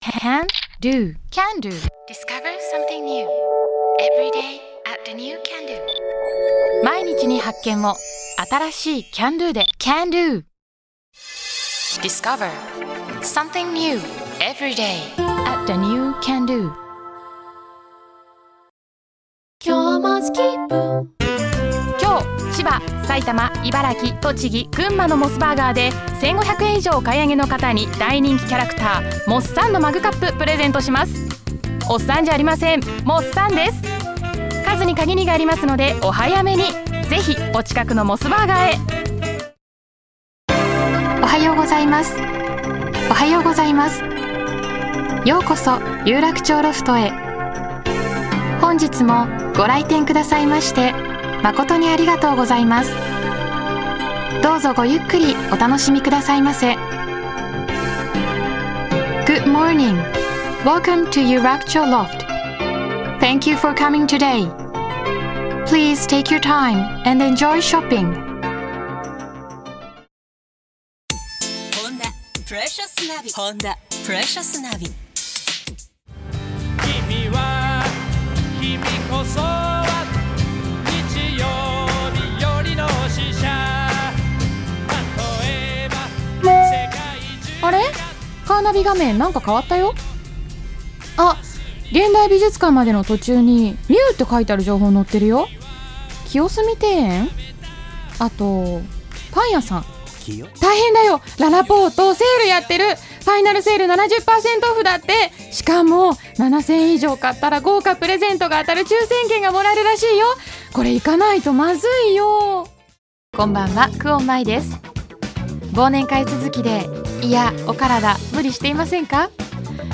ナチュラルな英語発音は、館内案内などの英語バージョンにも
Voice Reel
Voice-Reel.mp3